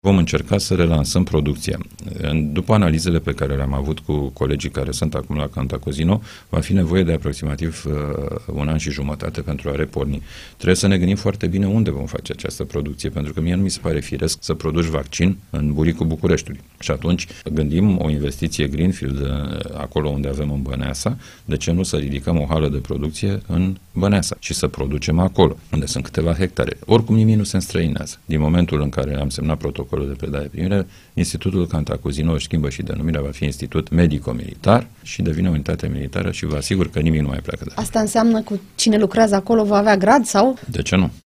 Invitat la Interviurile Europa FM, ministrul Apărării, Mihai Fifor, a anunțat că Executivul va adopta o ordonanță de urgență în acest sens.